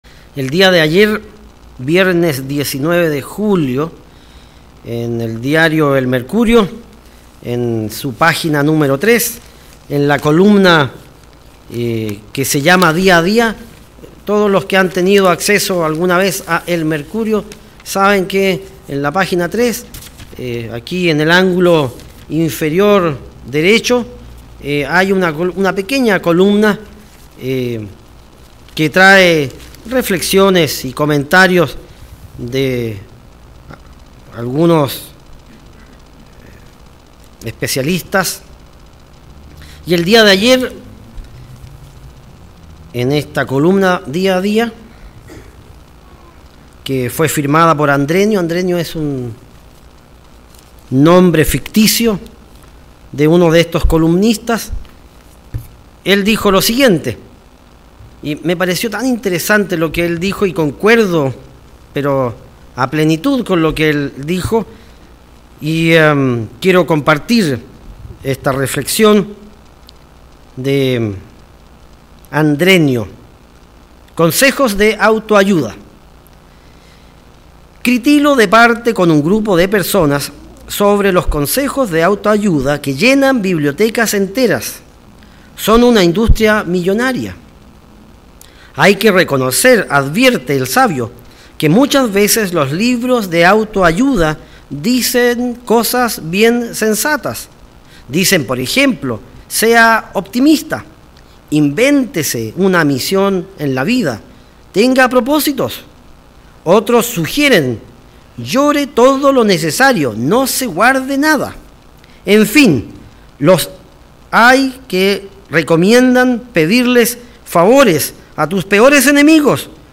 Lo difícil es hacerse de la voluntad y la energía para lograr un verdadero cambio. Mensaje entregado el 21 de julio de 2018.